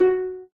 harp.ogg